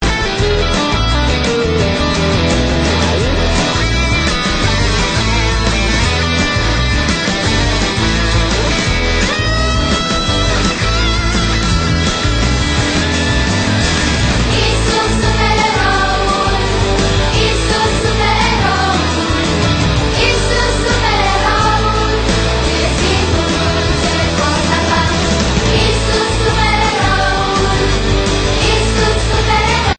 Cantece de inchinare pentru copii